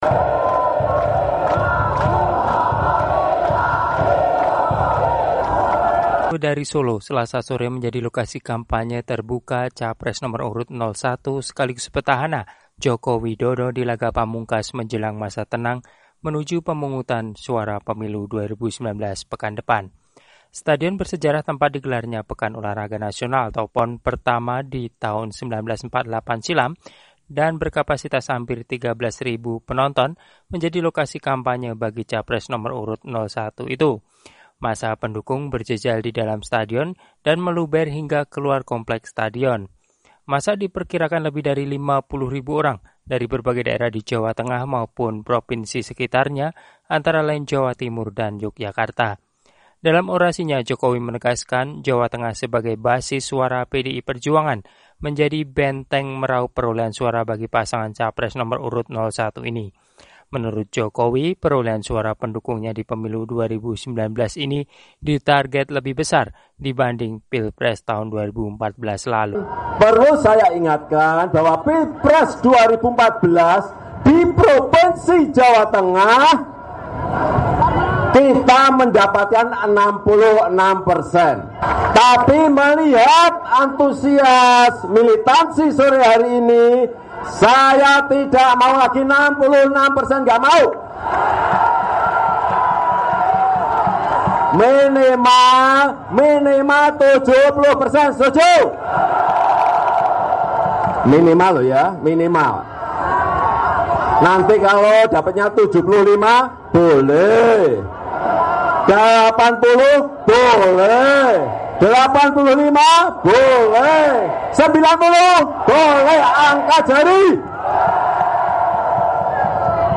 Massa pendukung berjejal di dalam stadion dan meluber hingga ke luar kompleks stadion tersebut.